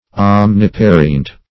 Search Result for " omniparient" : The Collaborative International Dictionary of English v.0.48: Omniparient \Om`ni*pa"ri*ent\, a. [L. omniparens all-producing; omnis all + parere to bring forth.] Producing or bringing forth all things; all-producing.